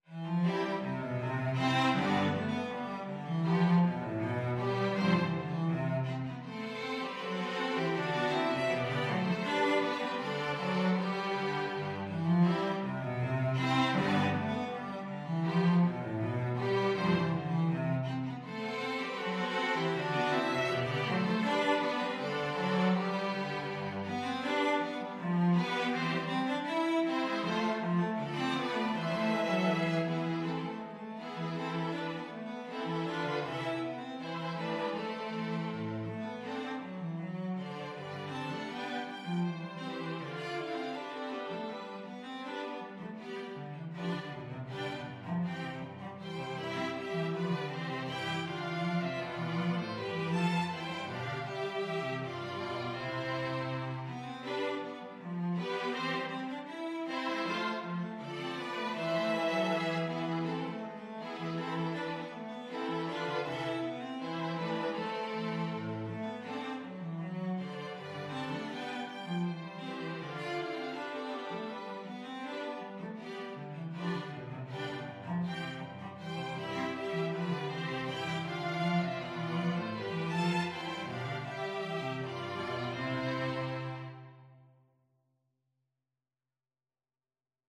Violin 1Violin 2ViolaCello
4/4 (View more 4/4 Music)
Classical (View more Classical String Quartet Music)